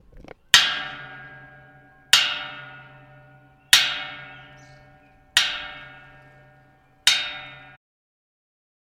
Hitting metal fence
abstract effect metal sound effect free sound royalty free Sound Effects